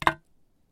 金属冲击
描述：把一个小鞭炮扔到一个金属板上。
标签： 爆竹 下落 金属 现场记录 命中 立体声 冲击
声道立体声